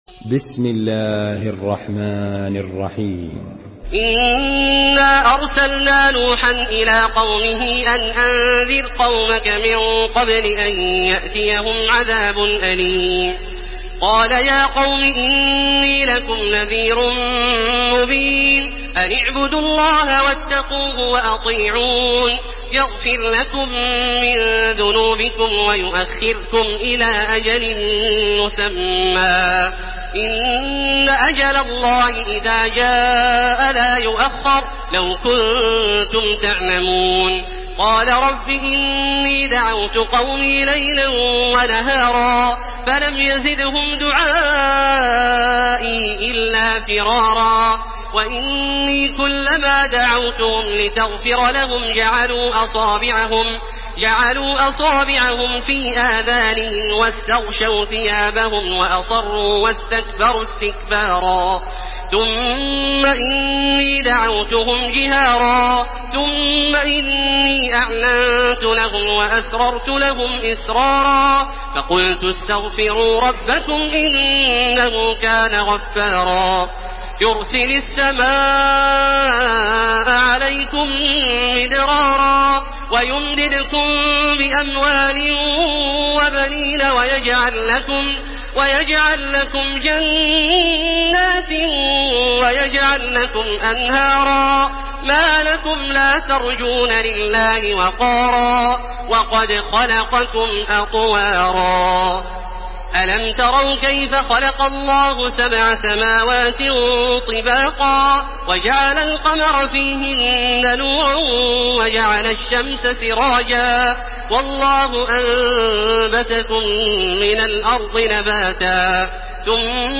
Surah نوح MP3 in the Voice of تراويح الحرم المكي 1431 in حفص Narration
Listen and download the full recitation in MP3 format via direct and fast links in multiple qualities to your mobile phone.
مرتل